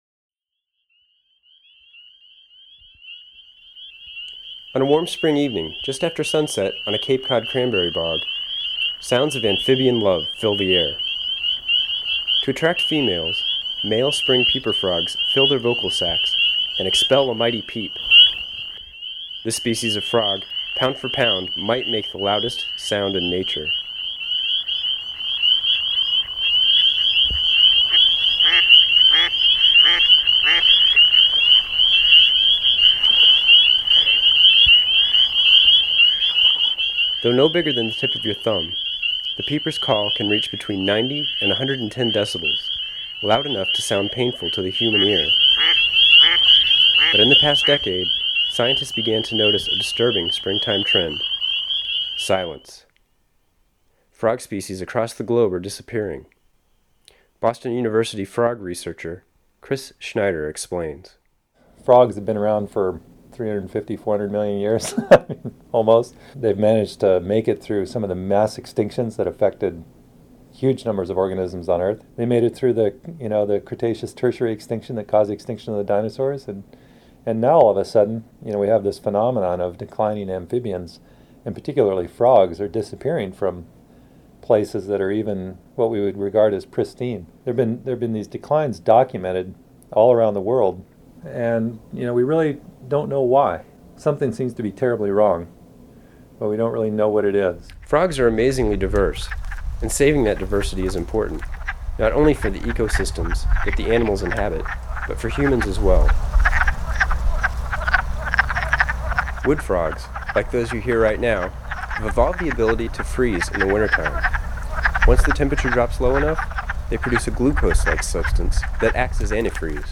Photos and sounds of frogs from Massachusetts and Panama.
frogbounced.mp3